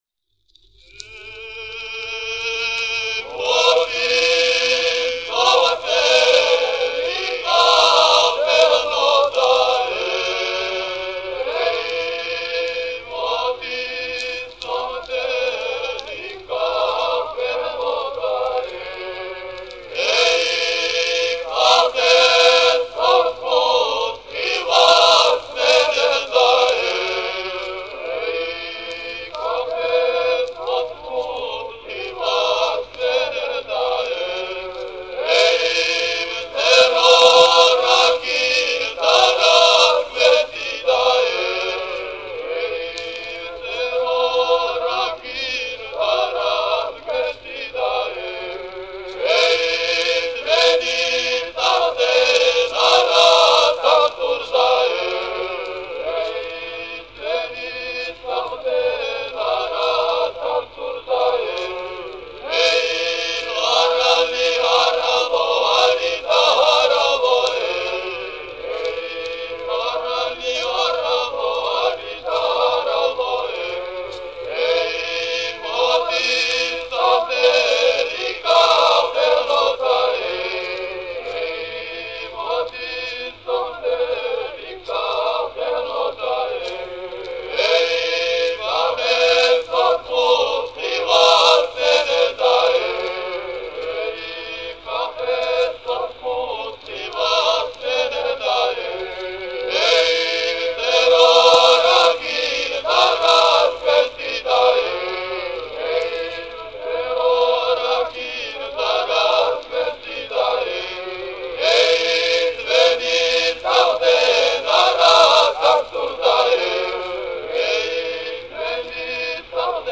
ensemble of singers (all recorded in 1914